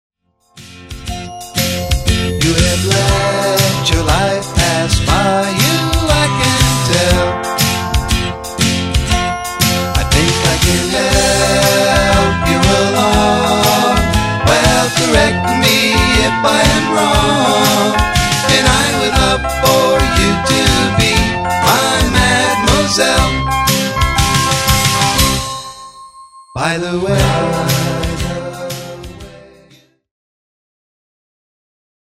USING AN ANALOG 8-TRACK.
THESE TUNES HAVE A RETRO FLARE TO THEM.
IF YOU'RE A FAN OF 60'S AND 70'S POP ROCK,